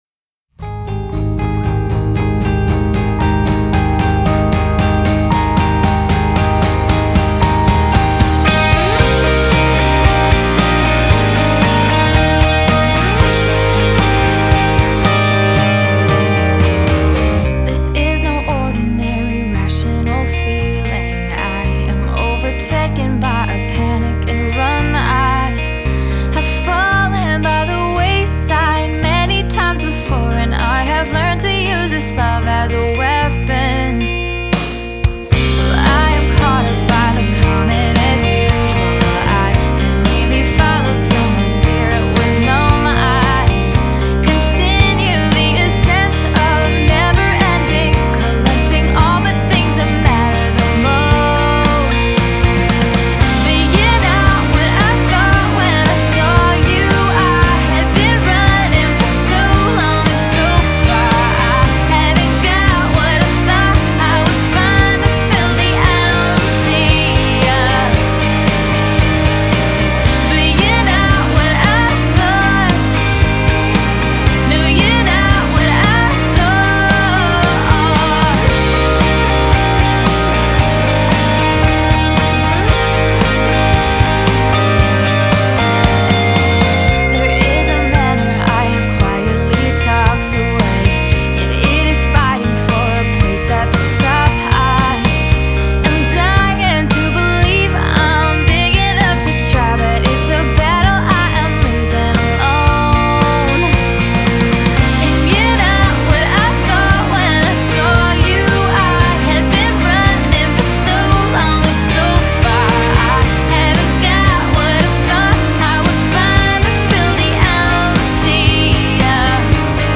Genre: Christian/Indiepop/Piano Rock/Female Vocal